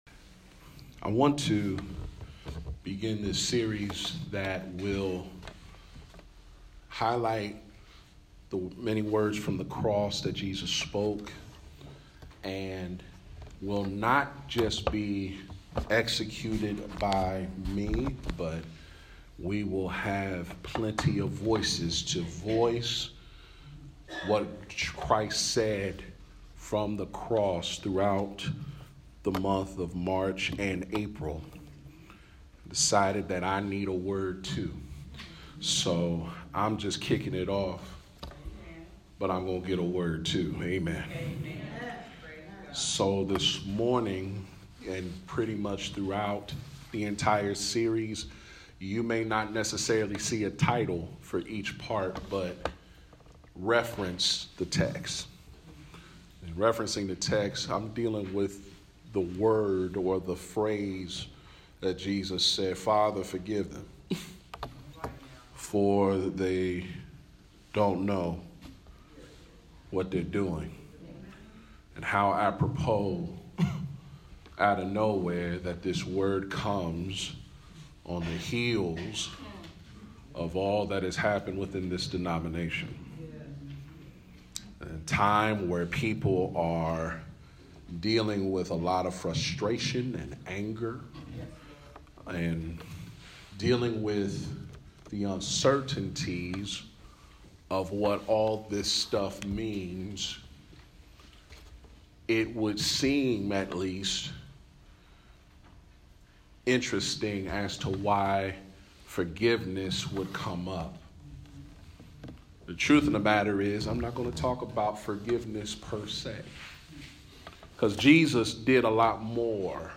The Journey to Victory is a message series focused on the last seven words of Jesus.